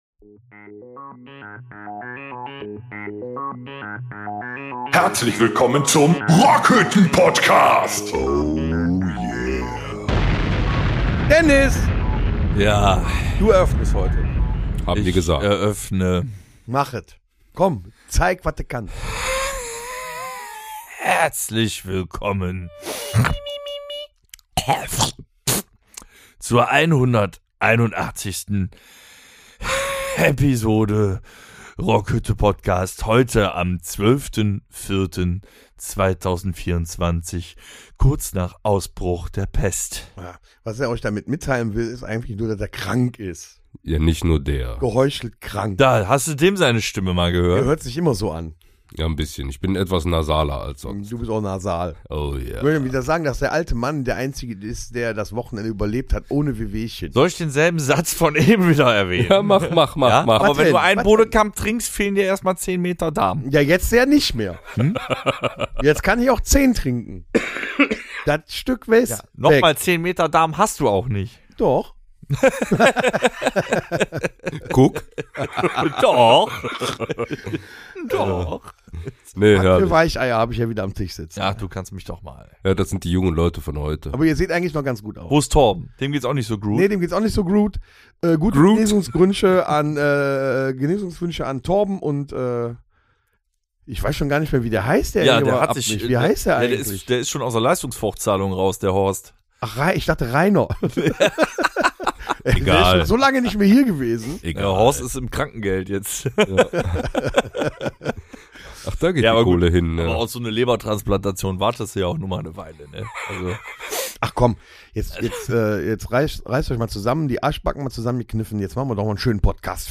Beschreibung vor 2 Jahren Heute sind wir nicht rotzevoll, aber voller Rotze! Die Tour hat ihre Spuren hinterlassen und ein Großteil der Band schleimt sich voller Inbrunst durch die Männergrippe.